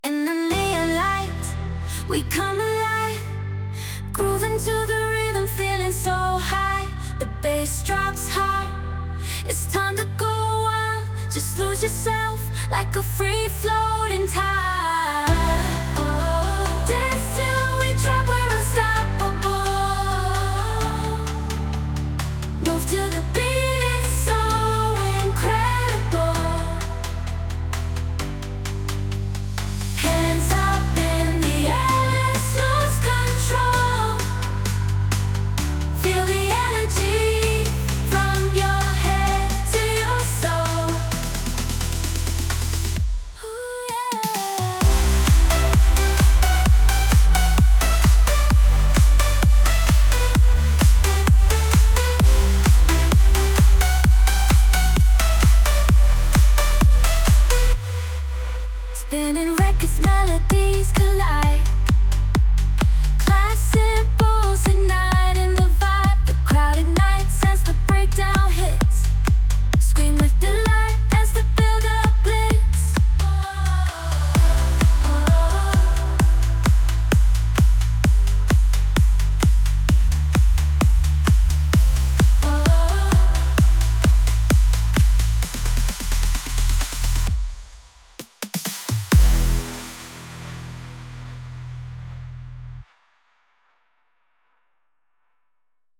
AI Pop / Dance
Experience the best of AI-generated pop music.